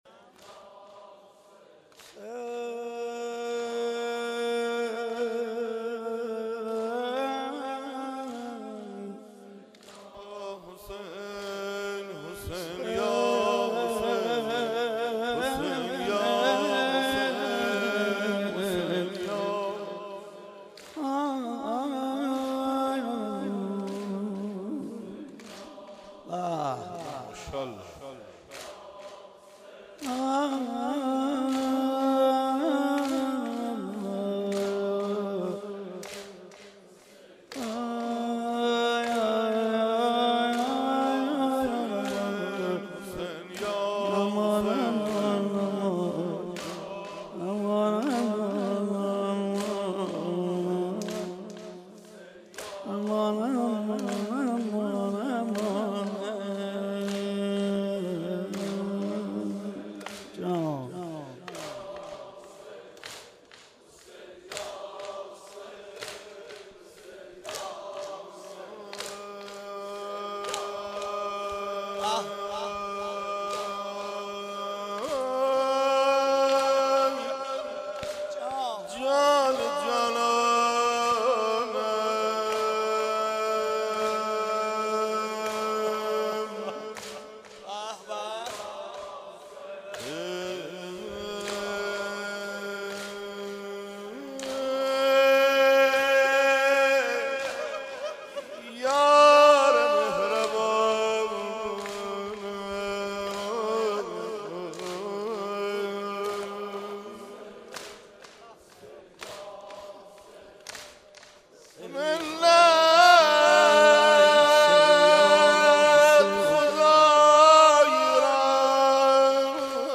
02.shabe 28 safar-zamine,1.mp3